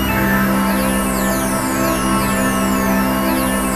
ATMOPAD20 -LR.wav